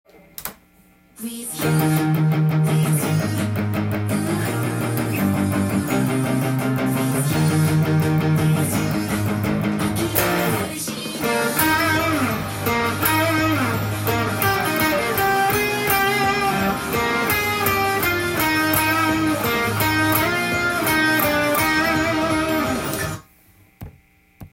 オリジナル音源にあわせて譜面通り弾いてみました
ノリノリのハイテンポナンバーのイントロ部分のtab譜です。
keyがE♭でパワーコードとドレミファソラシドで構成された
ビブラートを強めにかけて音に変化をつけていきます。